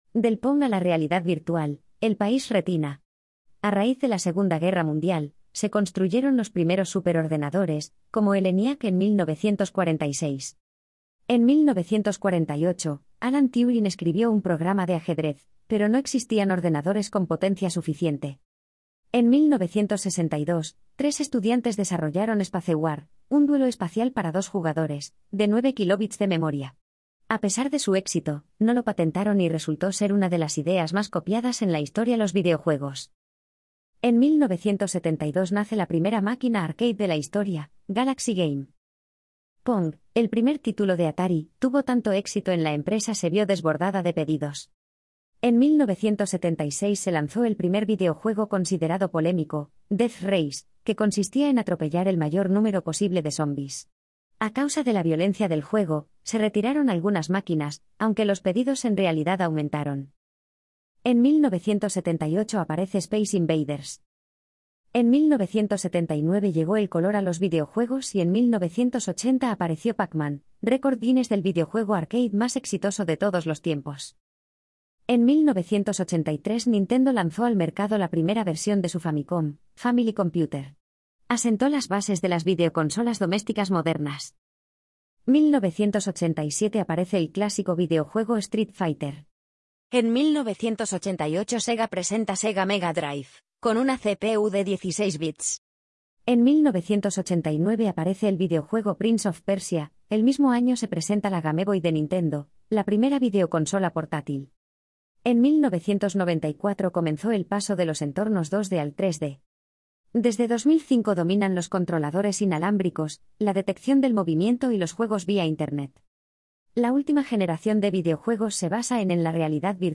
Ayuda de audio para el video: Del Pong a la realidad virtual (página 1 del carrusel)